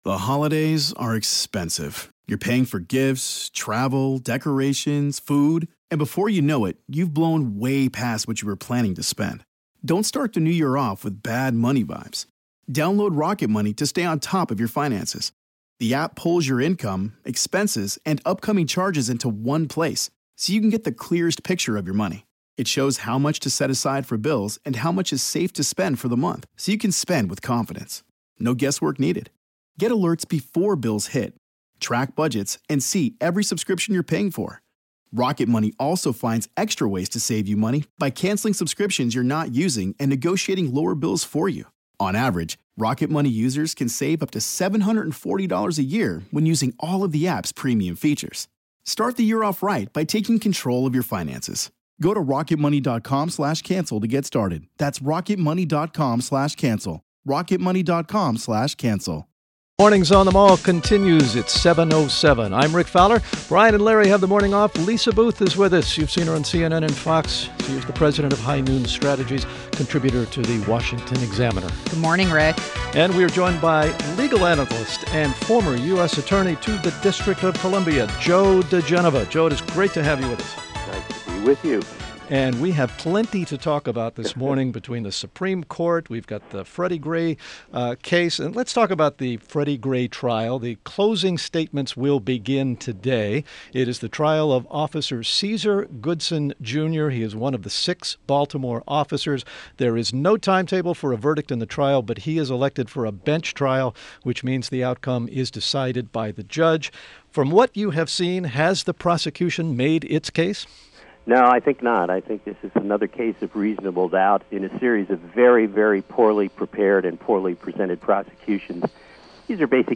WMAL Interview - Joe diGenova - 06.20.16
INTERVIEW — JOE DIGENOVA – legal analyst and former U.S. Attorney to the District of Columbia